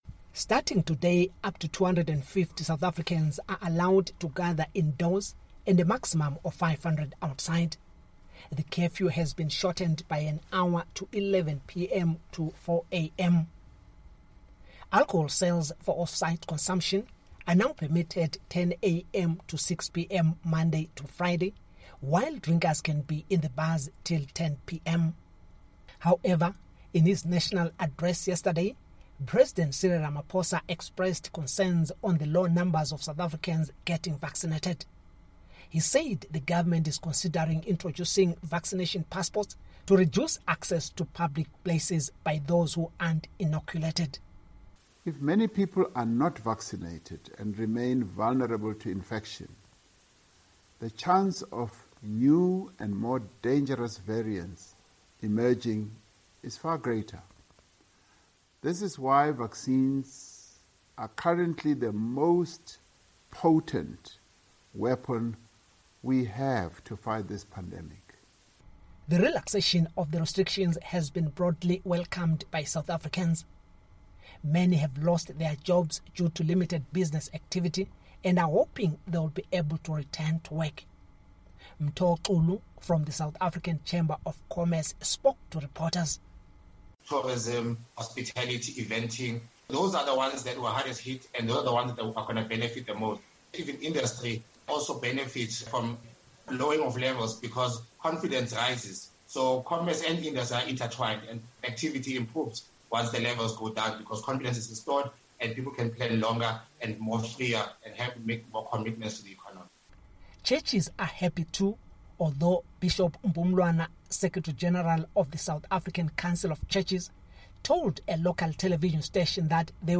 South Africans are hopeful after President Cyril Ramaphosa lowered the country's COVID-19 lockdown measures to level two -- one step before restrictions are completely removed. For Africa News Tonight